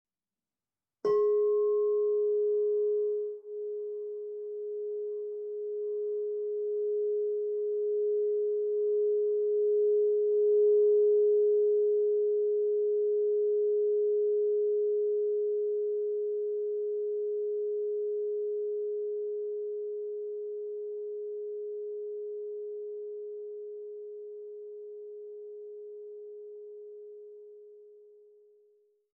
Meinl Sonic Energy 10" Solfeggio Crystal Singing Bowl Re 417 Hz (SOLCSB10-417)